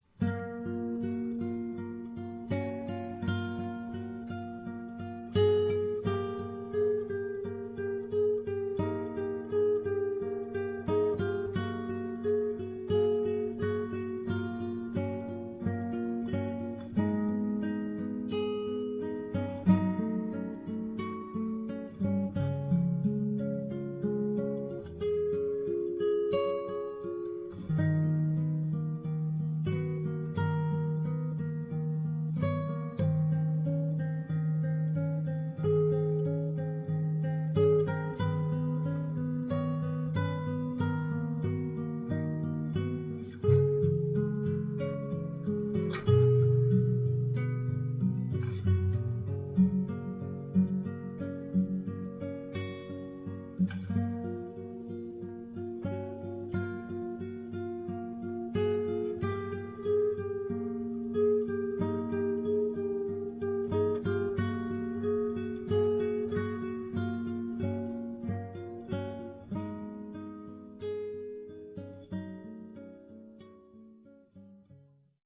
Tuning: EADGBE Key: ???
RealAudio format (Myself playing)
This is an arrangement for two guitars made by me